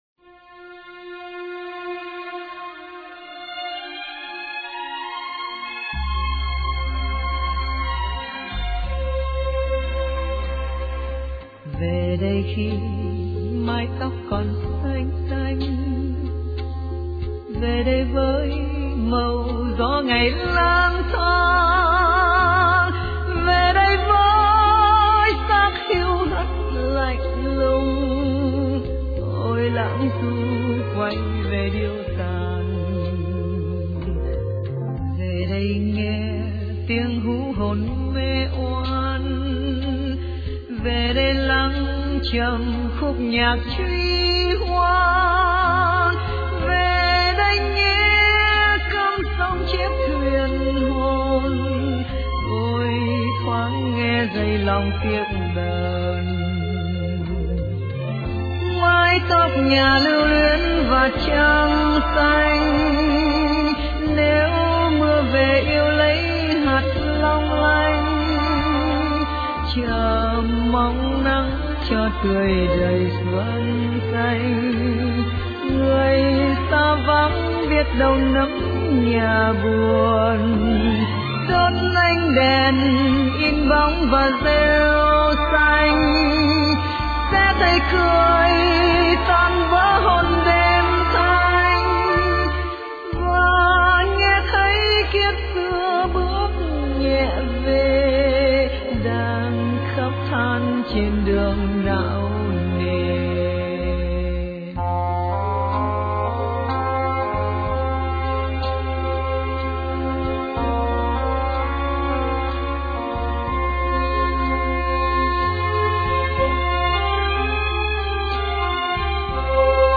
* Thể loại: Nhạc Ngoại Quốc